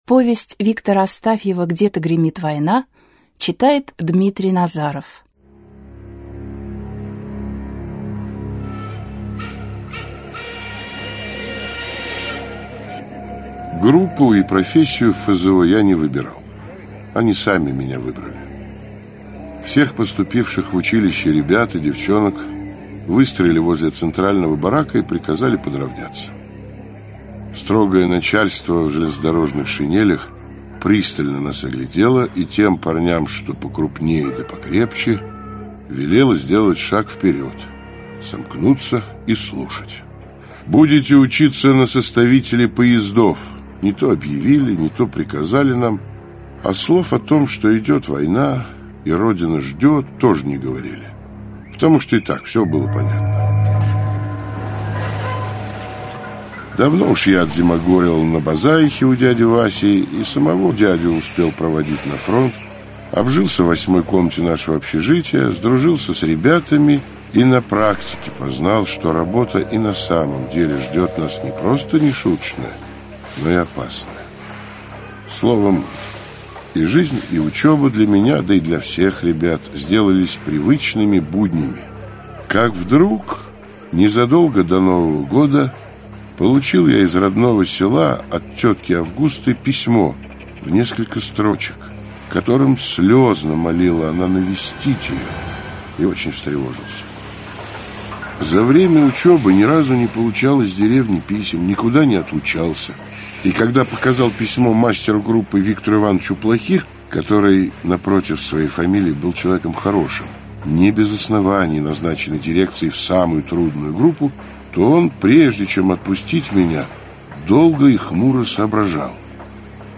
Aудиокнига Где-то гремит война Автор Виктор Астафьев Читает аудиокнигу Дмитрий Назаров.